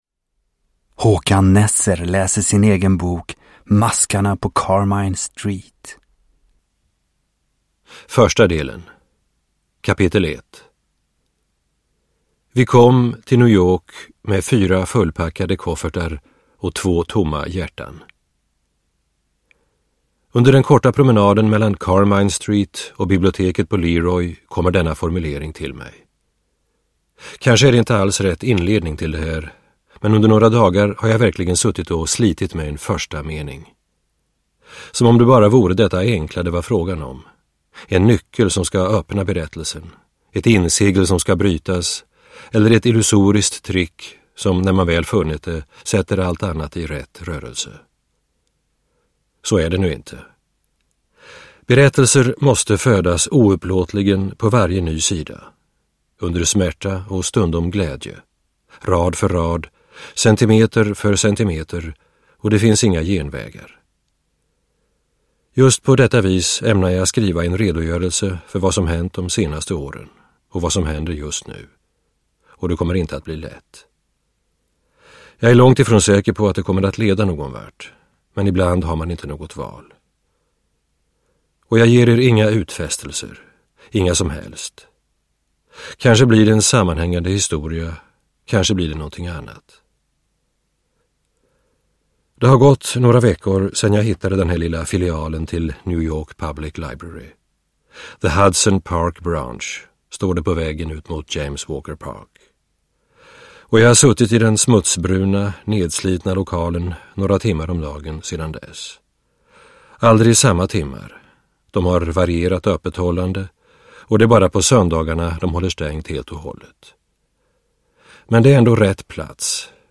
Uppläsare: Håkan Nesser
Ljudbok